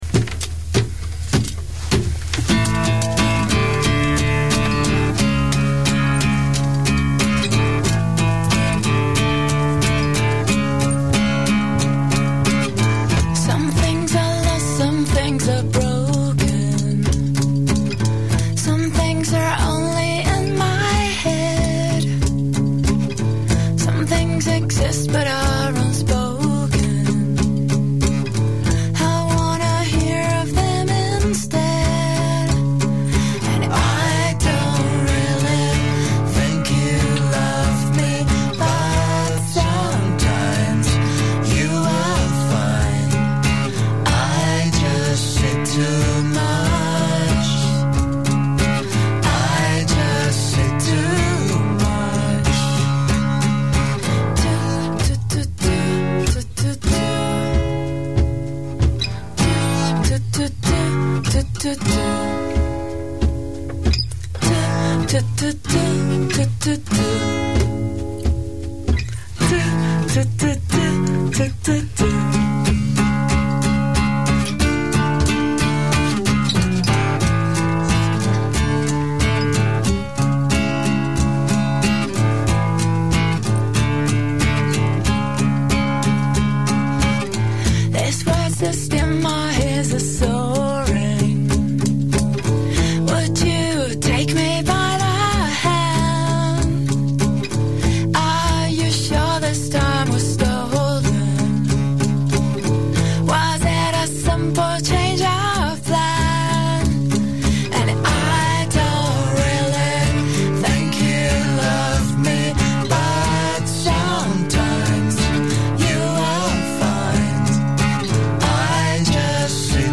un paio di canzoni unplugged